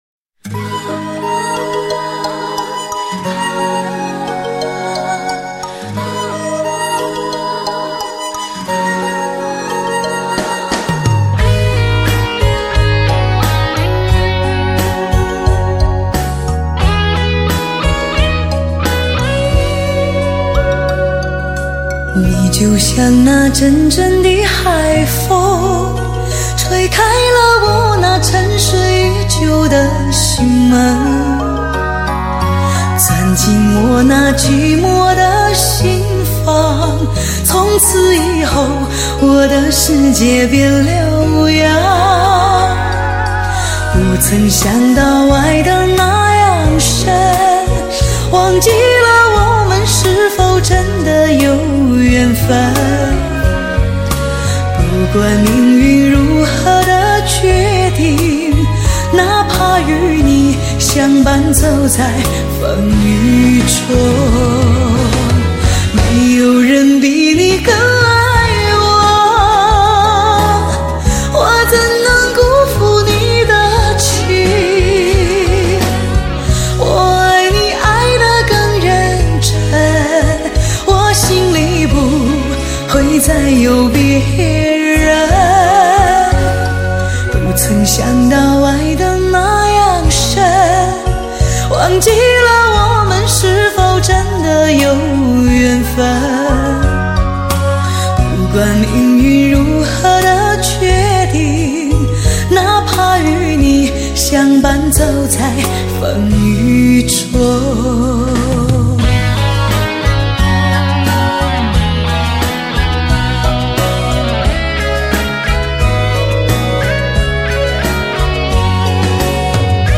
专辑格式：DTS-CD-5.1声道
无可言喻的感动，余音绕梁歌声蜕变，极度诱人，低吟浅唱，潜在着一股将人骨变酥的魔力。。。